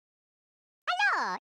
SFX_Hello.mp3